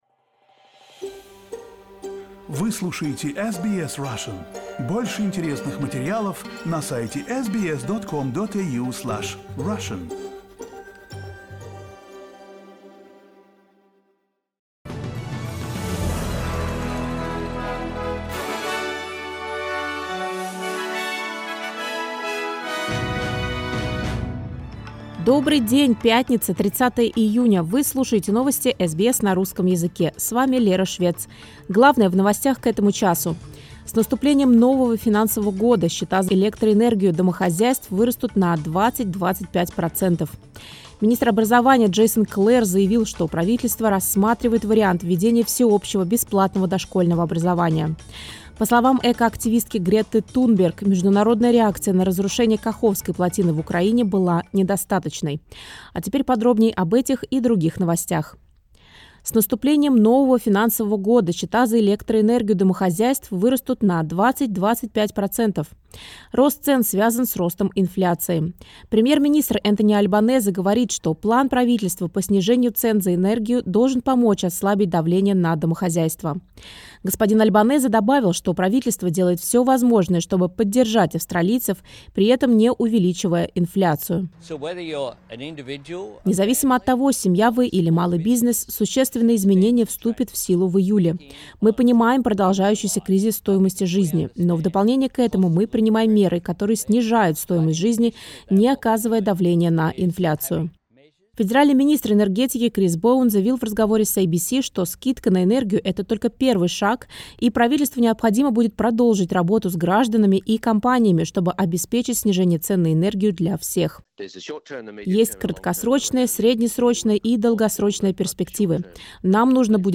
SBS news in Russian — 30.06.2023